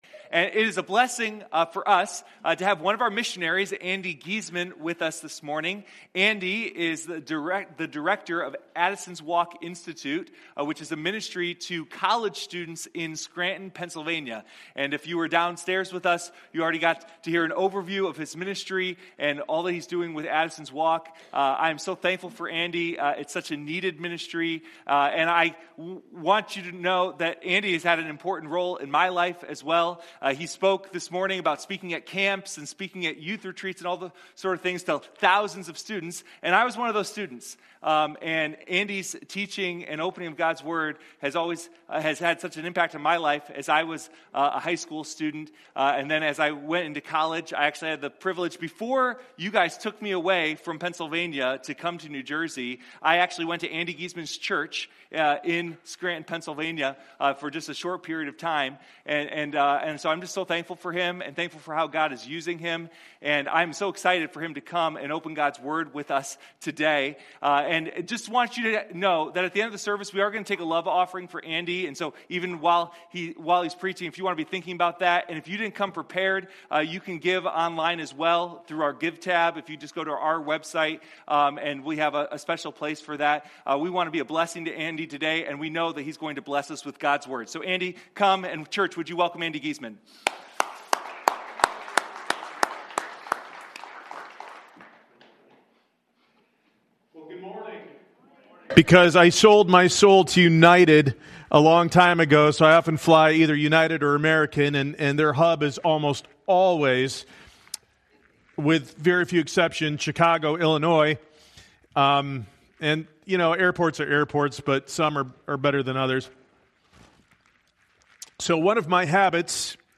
Sunday Morning Guest Speaker